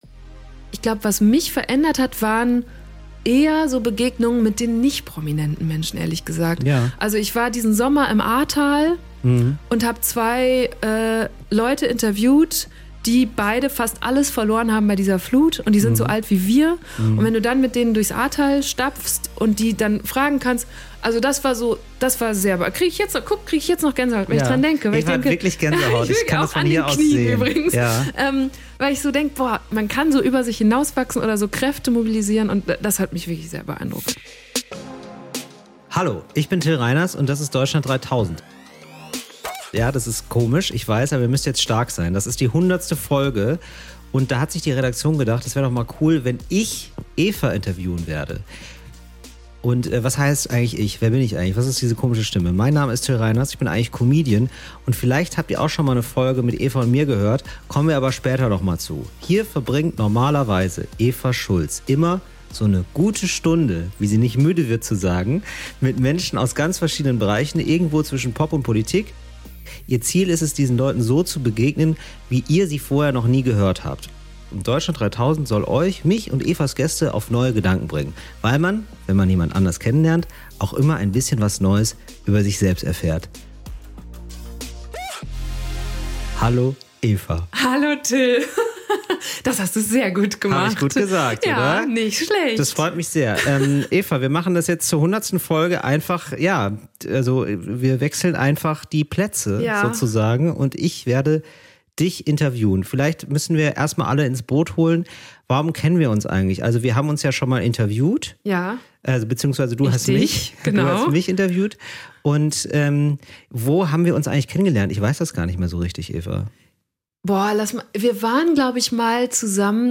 Das ist die 100. Folge und da hat sich die Redaktion gedacht: Wäre doch mal cool, wenn Eva interviewt wird.